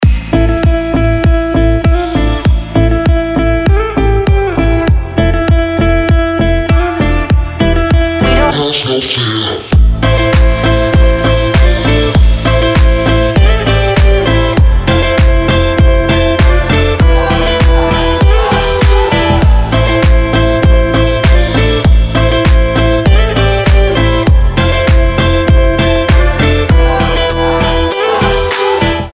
Танцевальные рингтоны , Рингтоны без слов , Гитара
Deep house
Клубные